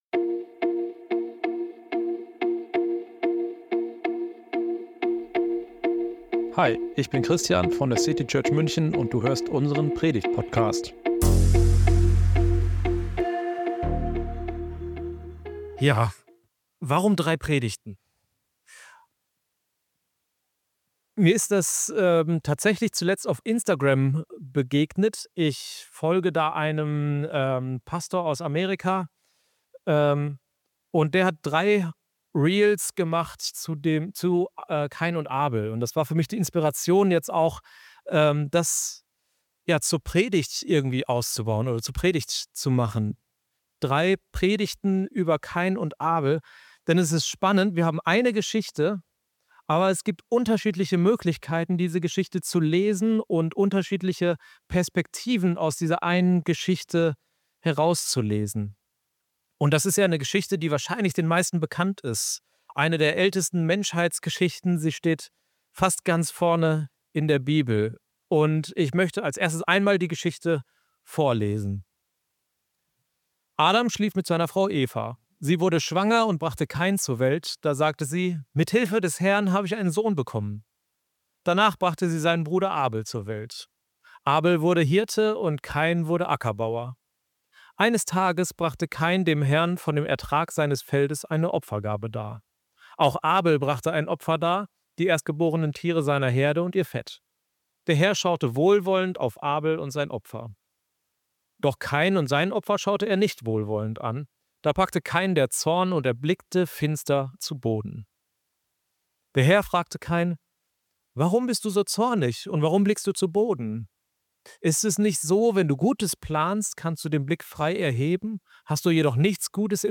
Diese Woche schauen wir neu auf die Geschichte von Kain und Abel. Drei kurze Mini-Predigten – drei Blickwinkel.